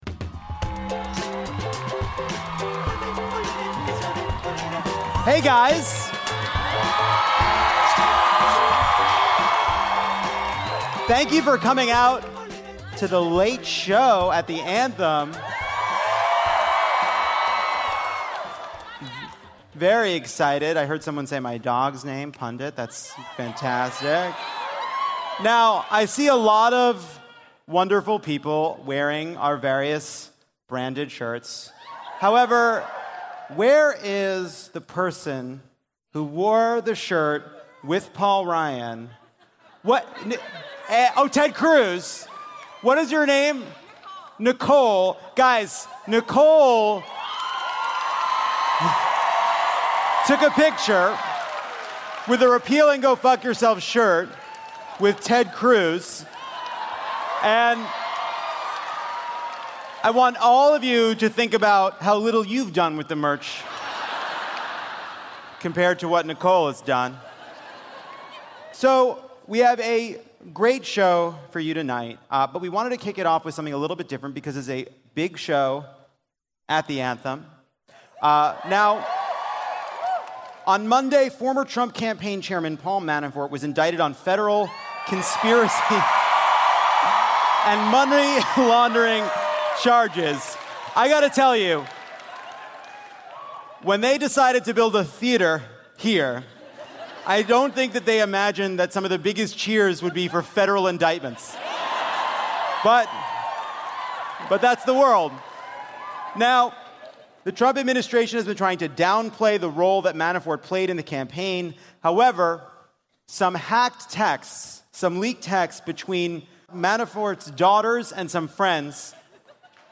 a dramatic reading of a very cool text chain, a few choice rants, and more.